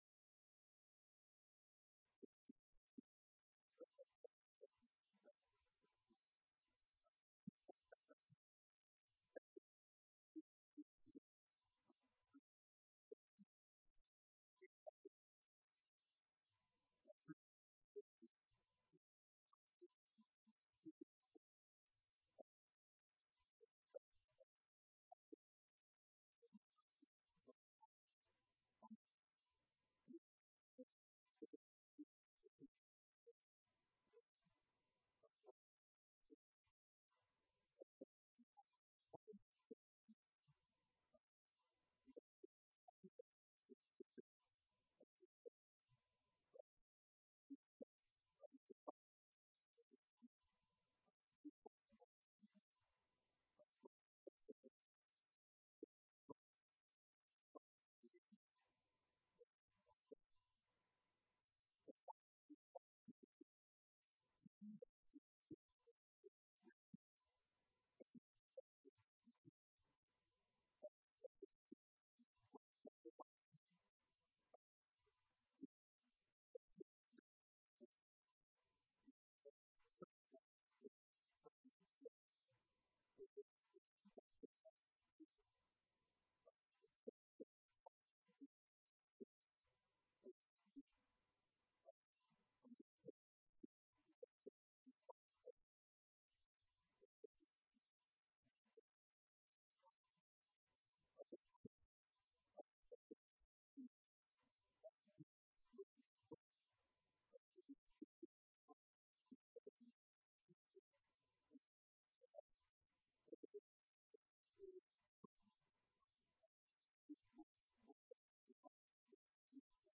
Event: 2019 Men's Development Conference
lecture